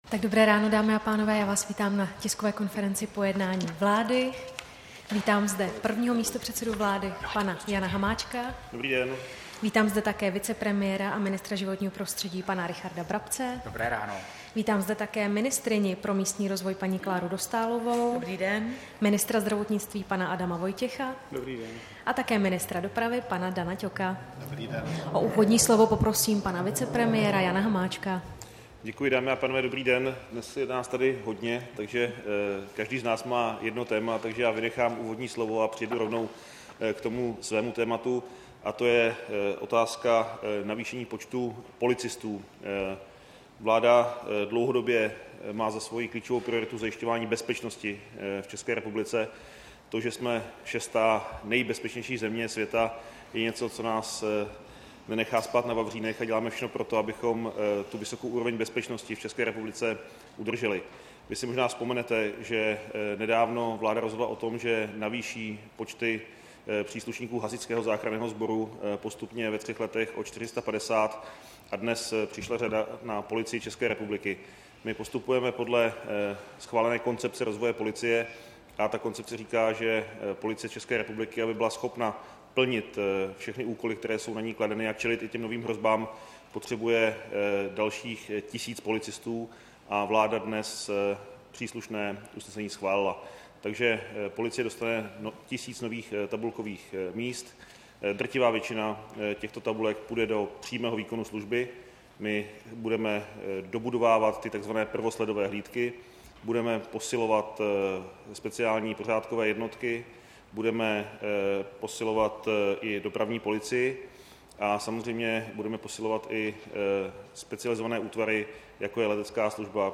Tisková konference po jednání vlády, 17. října 2018